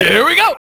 Can you place this MK3 speech?
mk3-here-we-go.wav